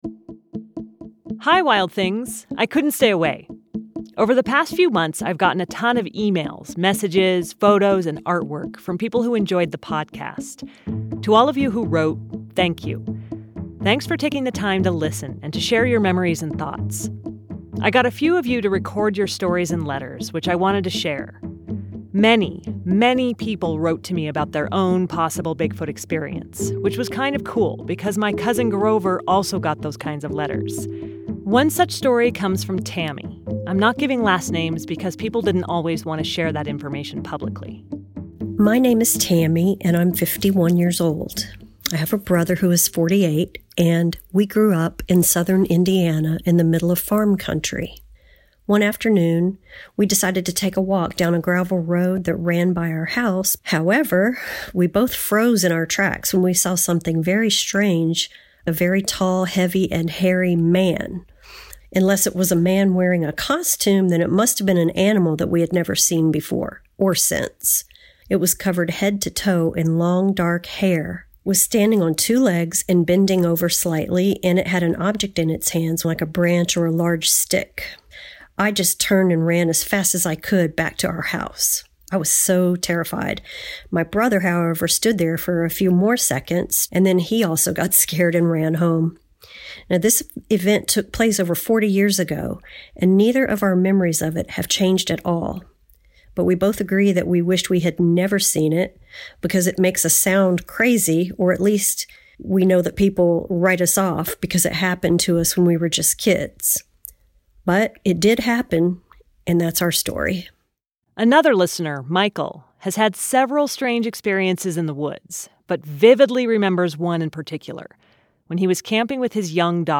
In a special bonus letters segment, a musical performance from a Wild Thing fan, as well as some of your stories and letter…